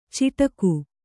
♪ ciṭaku